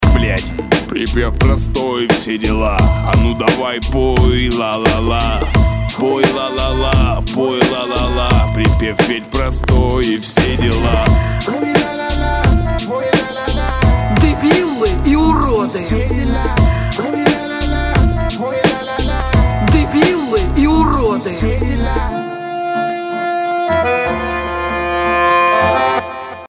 • Качество: 192, Stereo
Нецензурная лексика!